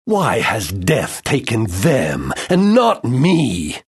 Hlášok je nepreberné množstvo a sú fakt kvalitne nadabované, intonácia a výber hlasov sedia presne do situácií, kedy postava povie svoju repliku.